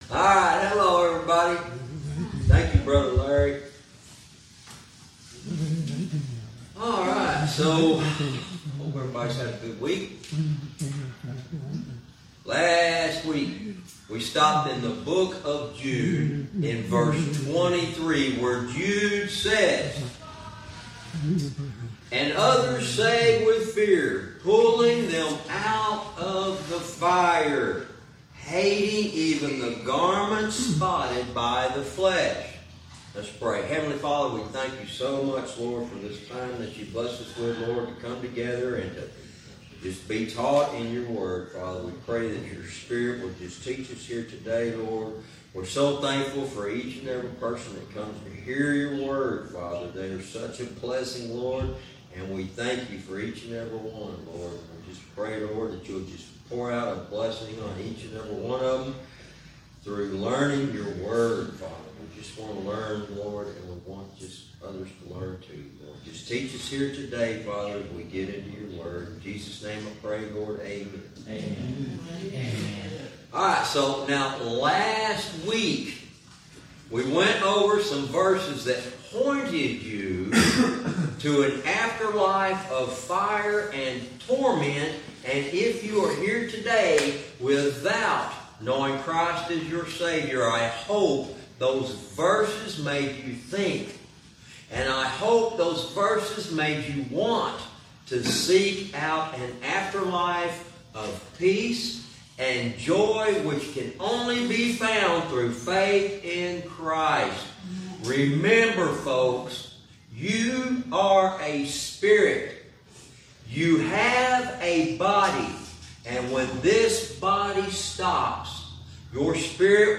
Verse by verse teaching - Jude lesson 103 verse 23